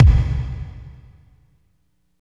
31.10 KICK.wav